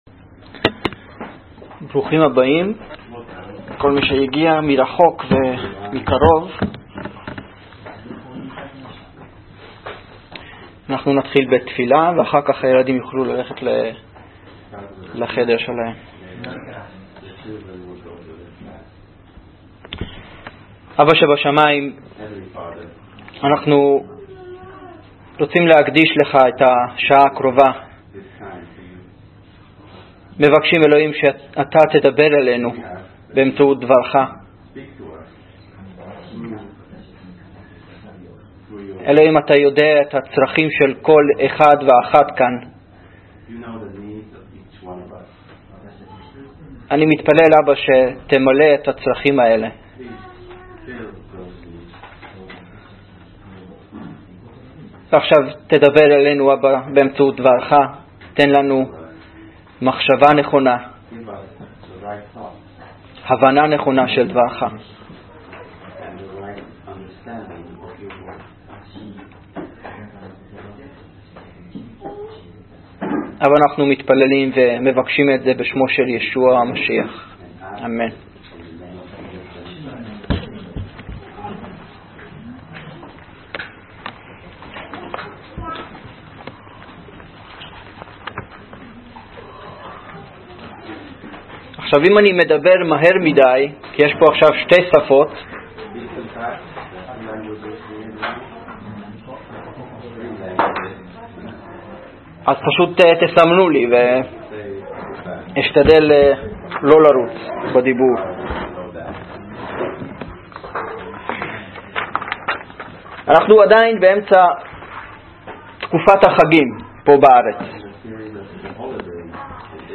דצמבר 24, 2019 דרשות לפי נושאים